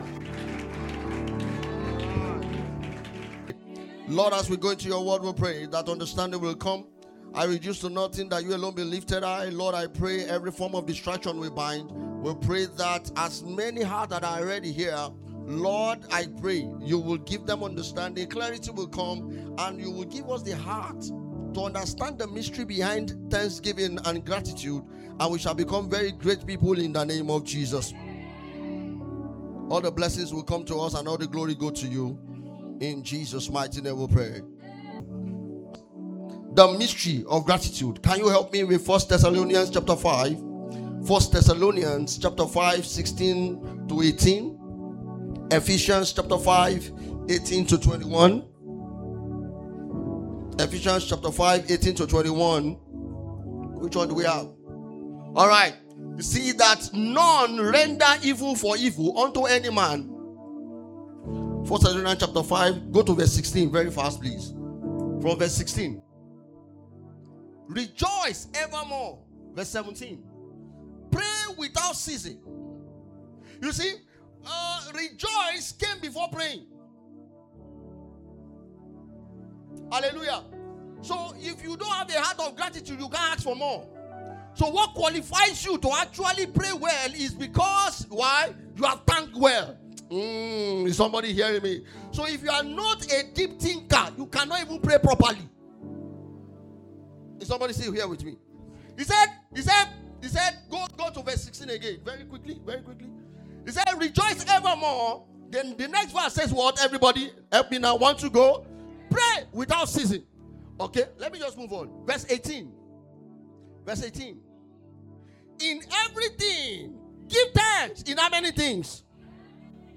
Service Type: Wednesday Service